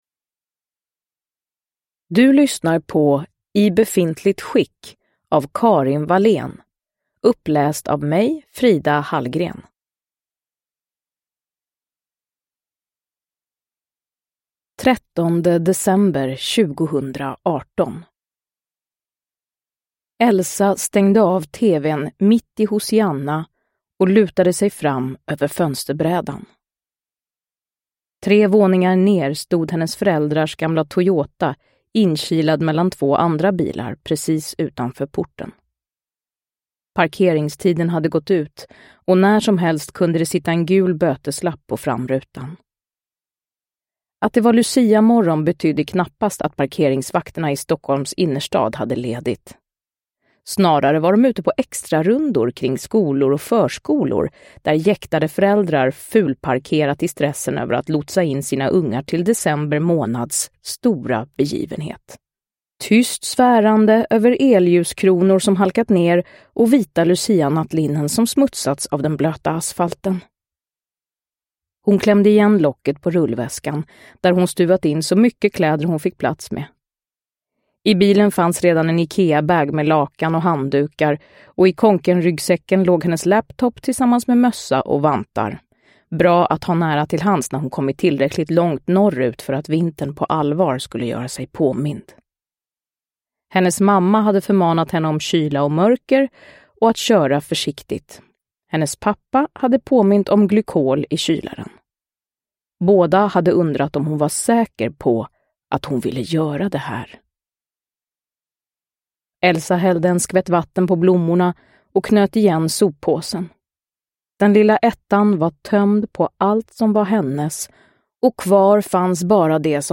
I befintligt skick – Ljudbok – Laddas ner
Uppläsare: Frida Hallgren